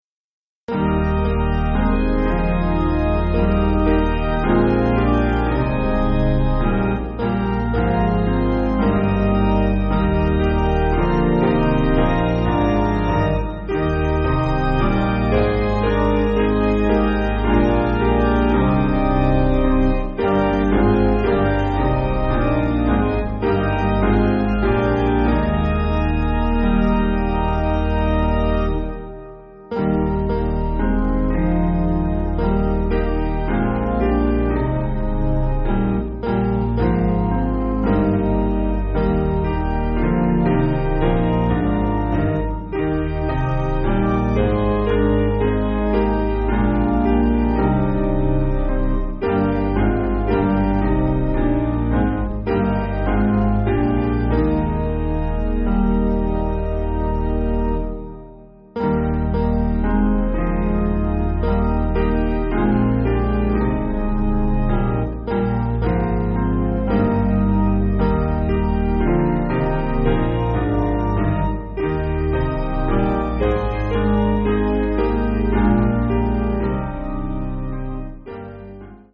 Basic Piano & Organ
(CM)   5/Eb